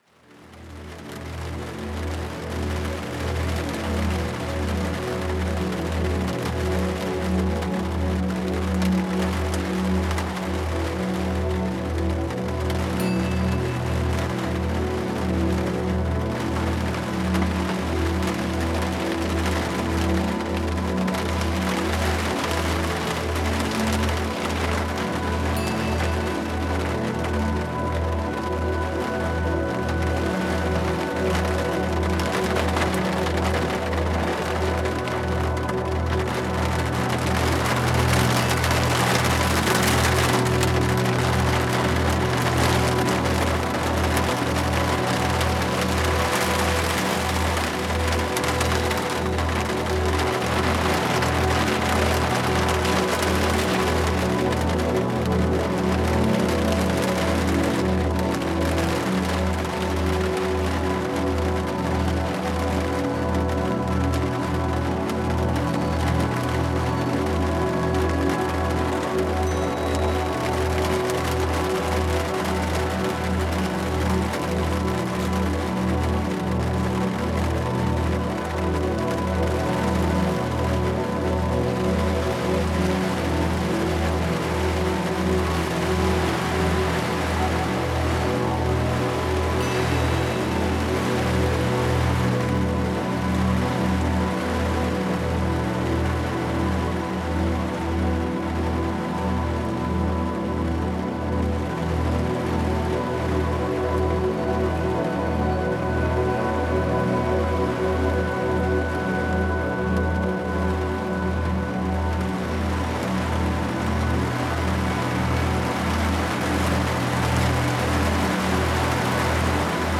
Fermez les yeux… et laissez la pluie vous envelopper.